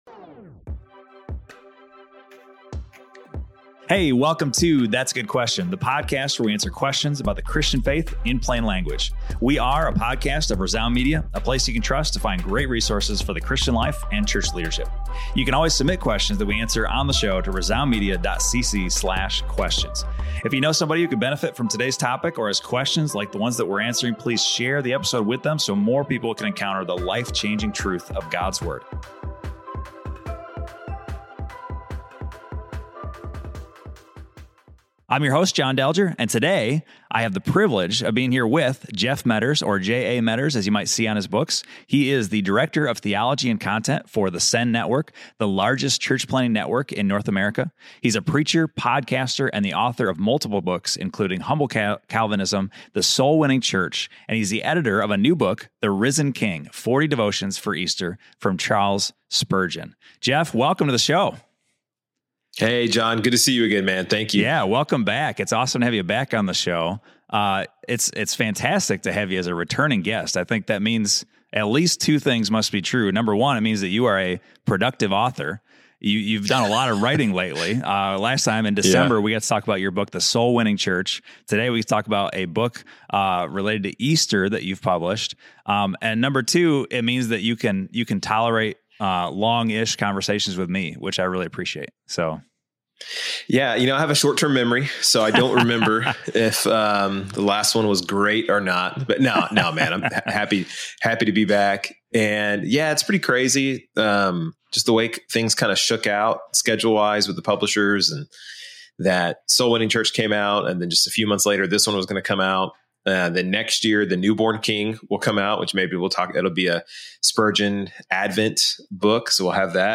Daily Grace and an Empty Tomb: A Conversation